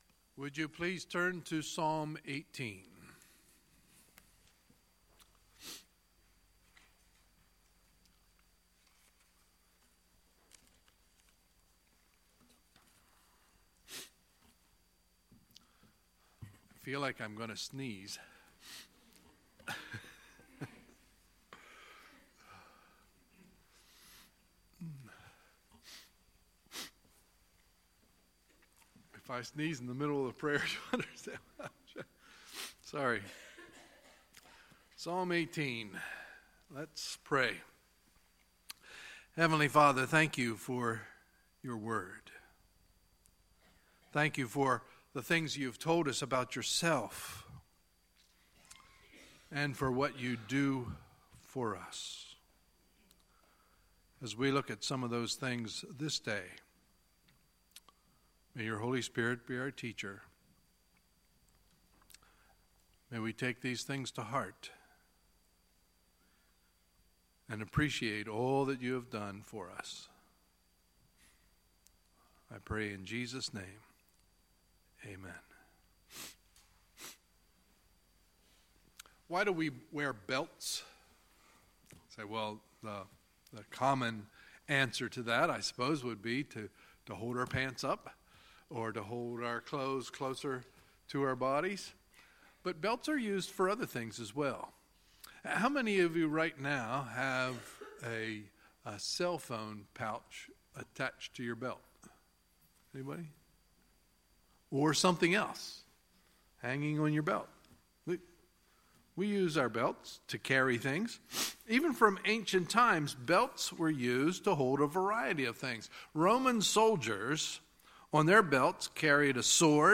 Sunday, November 5, 2017 – Sunday Morning Service